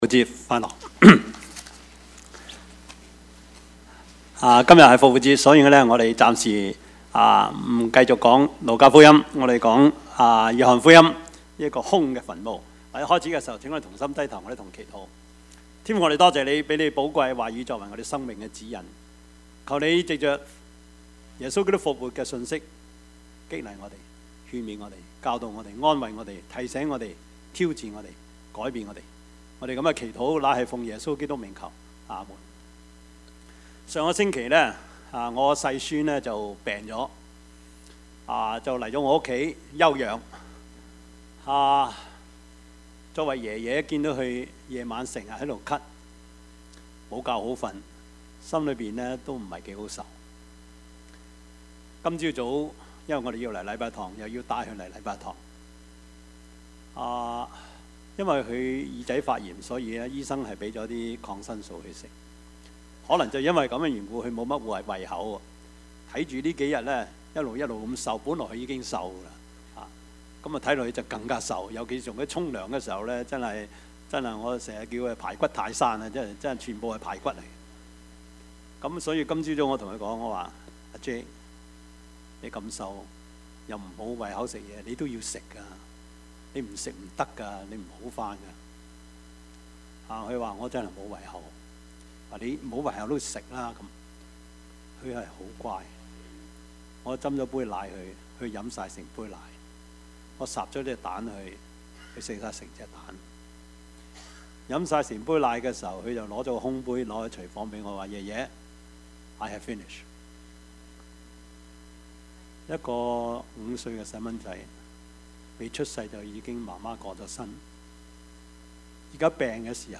Service Type: 主日崇拜
Topics: 主日證道 « 仍是有望 梁發與勸世良言 »